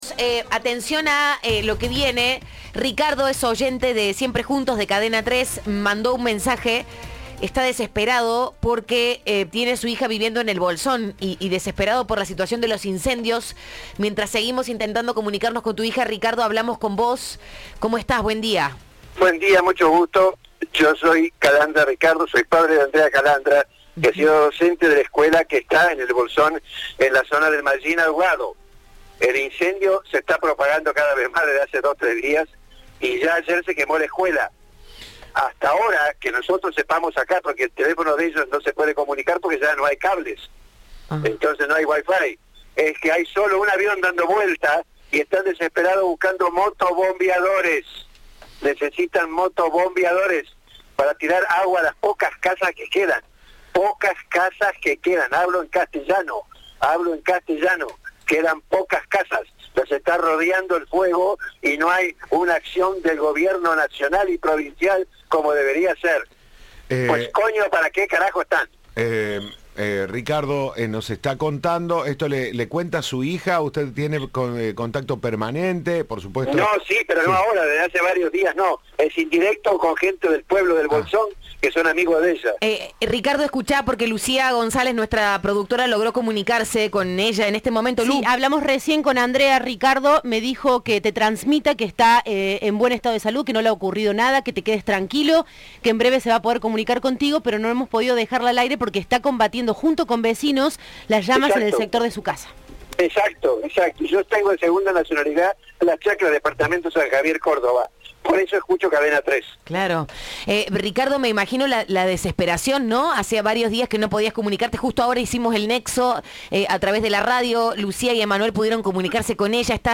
Entrevista de Siempre Juntos.